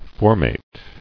[for·mate]